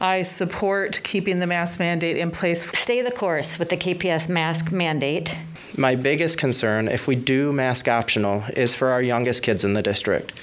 Kalamazoo Public Schools Board of Education meeting March 17, 2022
Several members of the public spoke about masks last night, and all of them were in favor of keeping the mandate.